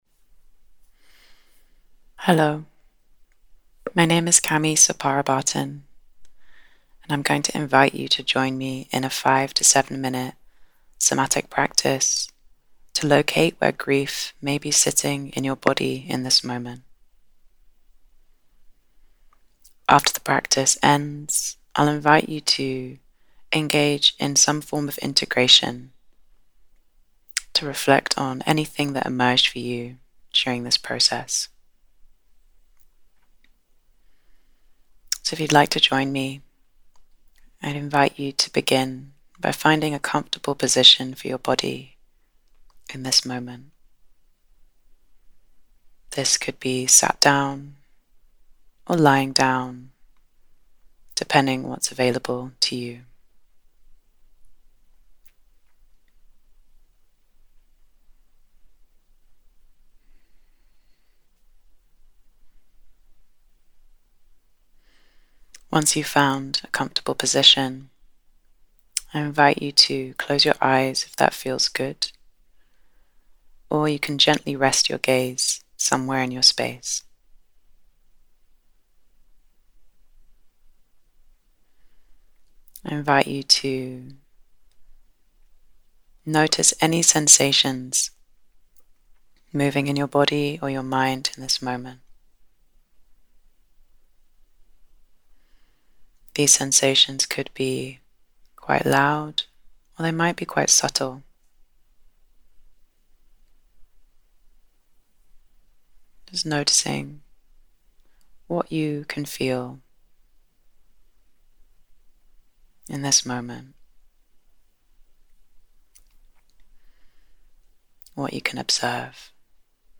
→ This will guide you through an embodied resourcing practice and guided meditation.